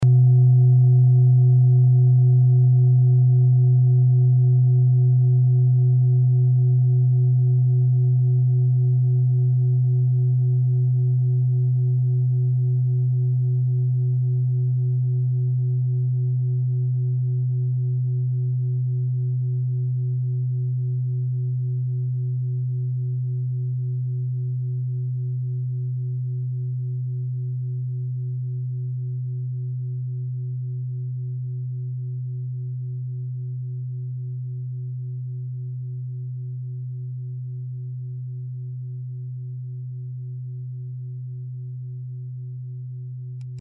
Klangschale Bengalen Nr.5
Klangschale-Gewicht: 1150g
Klangschale-Durchmesser: 20,0cm
(Ermittelt mit dem Filzklöppel oder Gummikernschlegel)
klangschale-ladakh-5.mp3